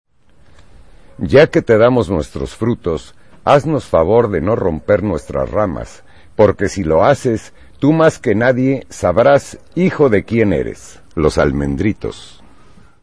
Lugar de la grabación: Municipio de Berriozábal, Chiapas; Mexico.
Equipo: Minidisc NetMD MD-N510, micrófono de construcción casera (más info)